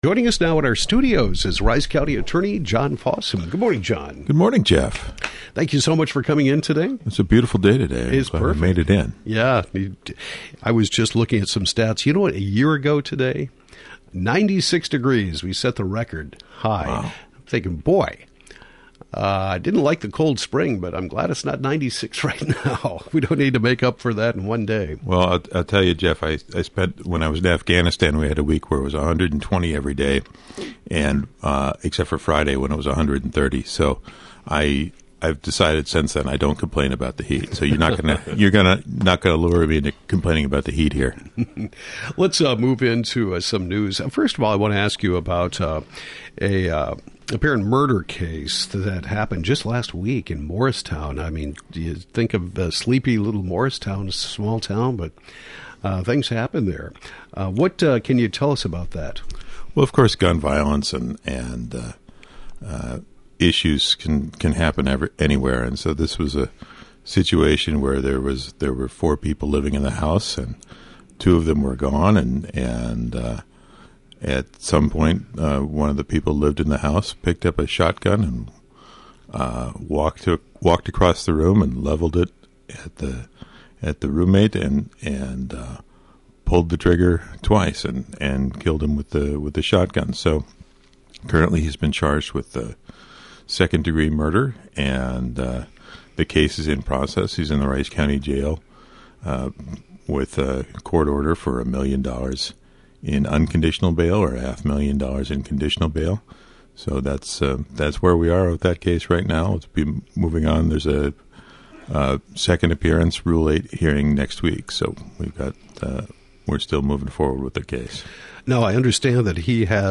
Rice County Attorney John Fossum talks about a recent murder in Morristown, and a grant proposal involving the police department, Healthy Community Initiative, community corrections and social services and the County Attorney’s office for an initiative to help provide treatment to people with low level drug offenses as opposed to being charged with a crime.